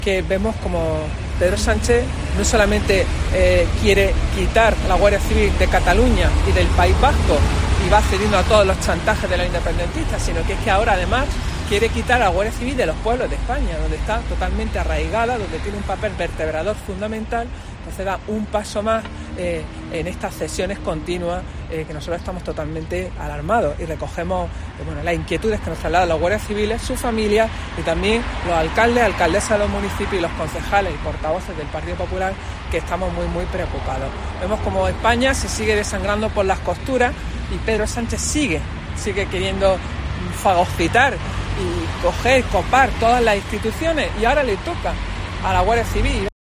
La senadora Inmaculada Hernández habla sobre el cierre de las casas cuartel de la Guardia civil